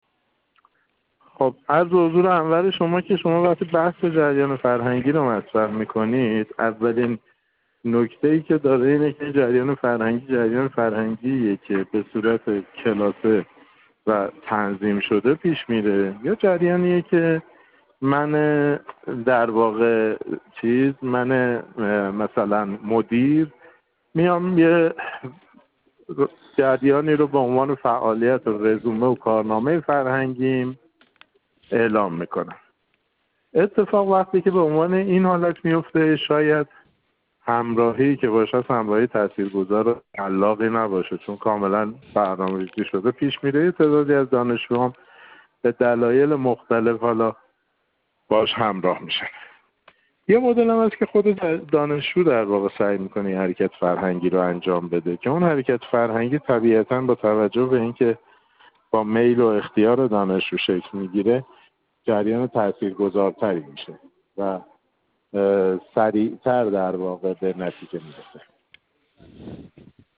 گفت‌و‌گویی